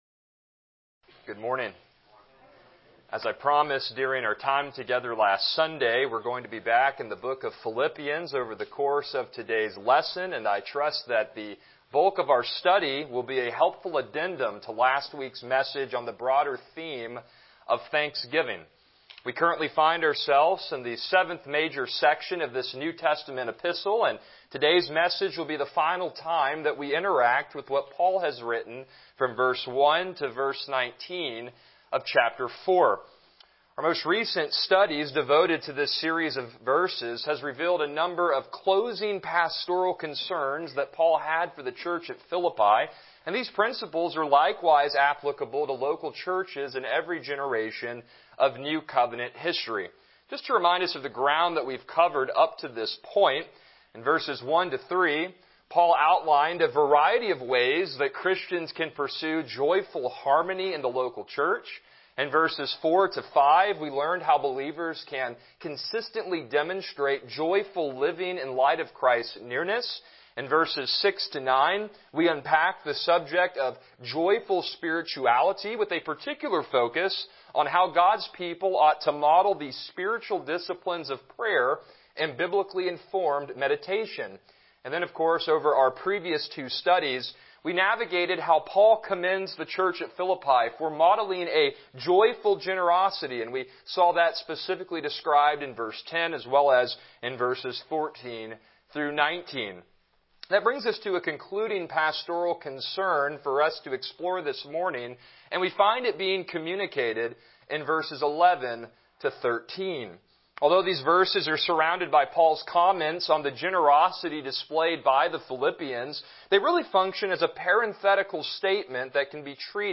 Passage: Philippians 4:11-13 Service Type: Morning Worship